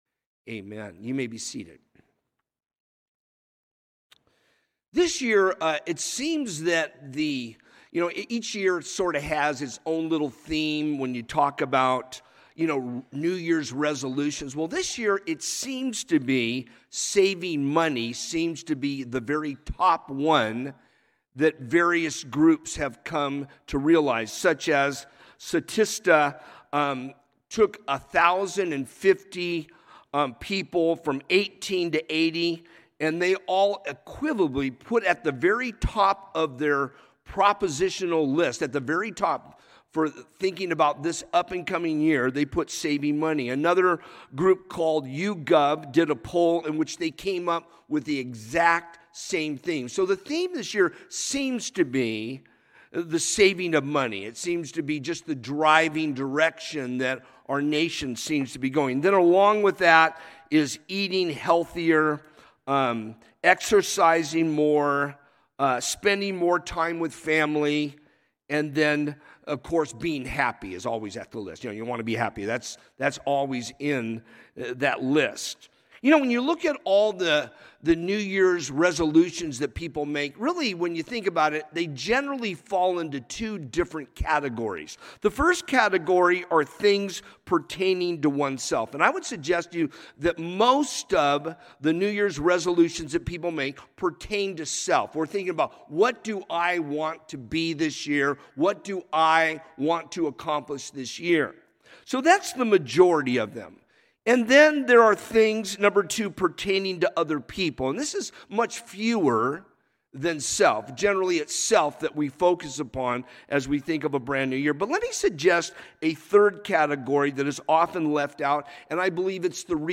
A message from the series "New Year's Service."